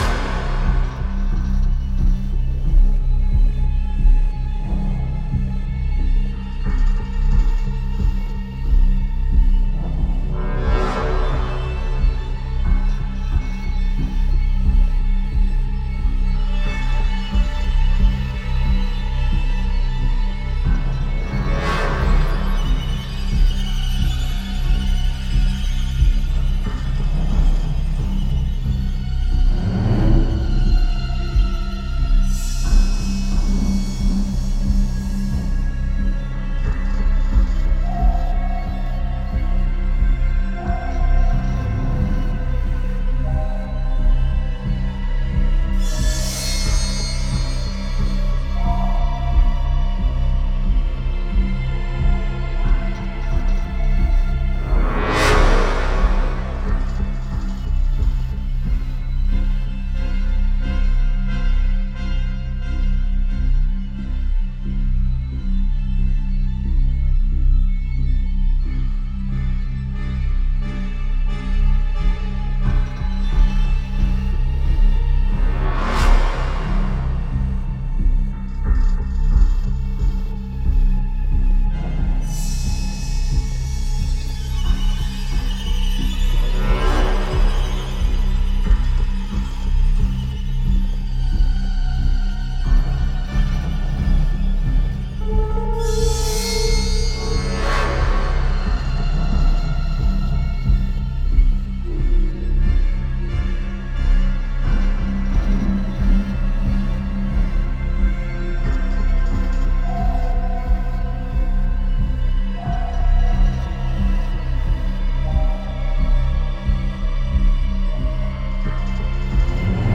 06 - Suspense